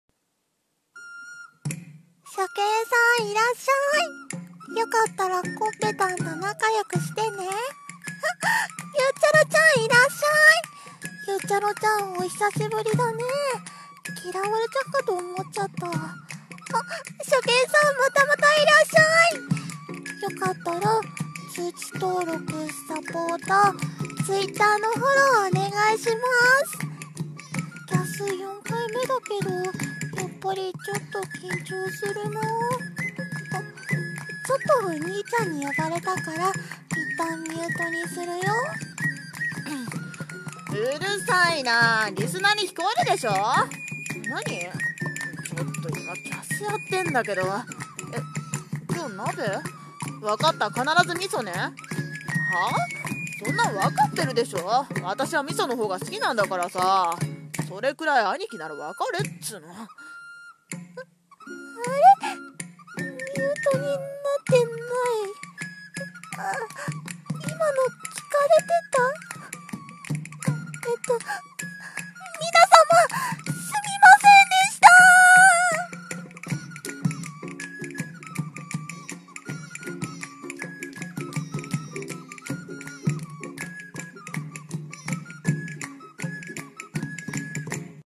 【声劇】萌え声が地声バレる【1人声劇】